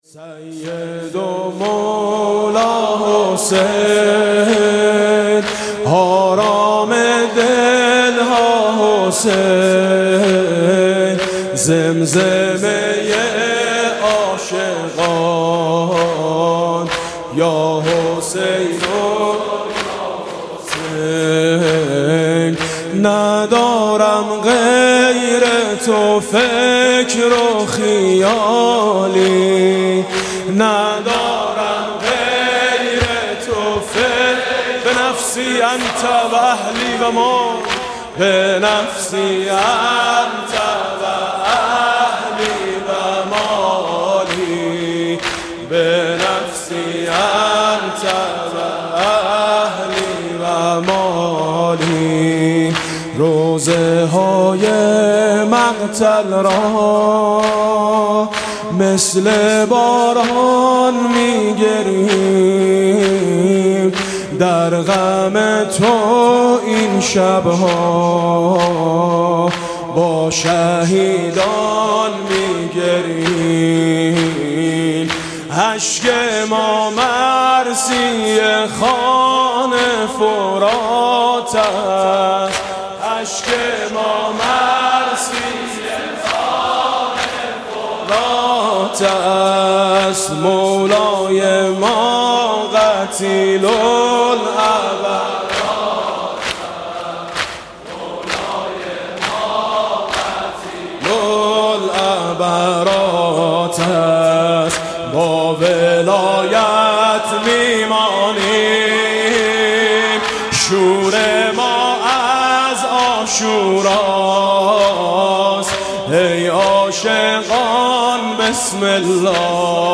دم پایانی